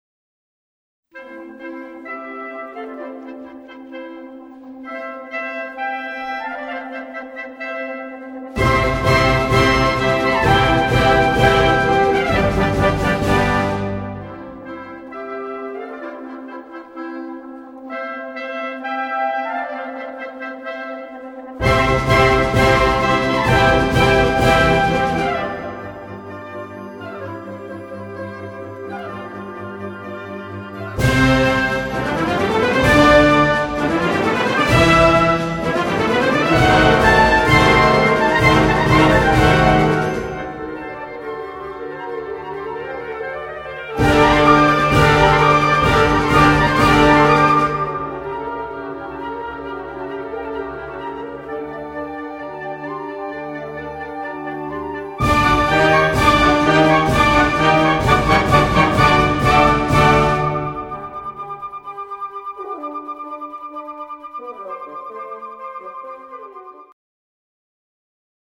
C Major（原調）
この曲の中には C Major などのスケールが満載。